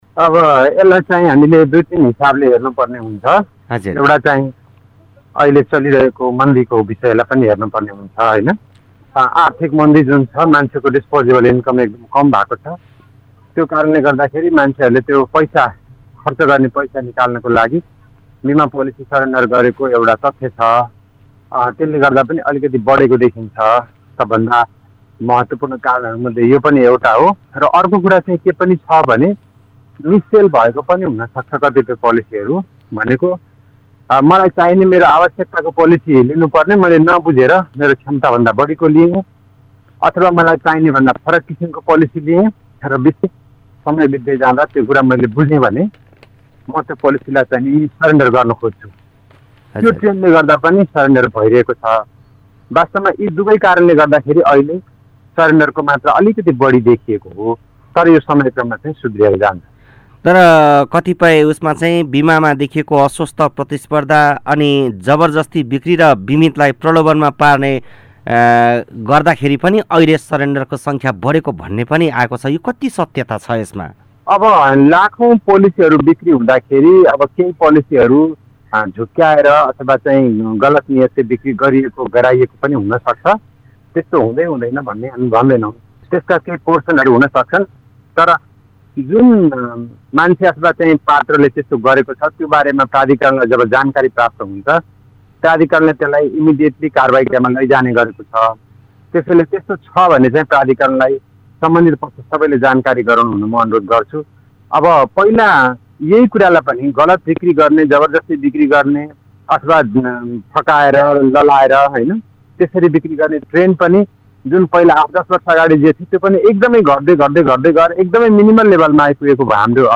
बाढी पहिरोको क्षतिबाट ९ अर्ब ५४ करोडको बीमा दाबी, किन बढ्यो सरेण्डर? (कुराकानी)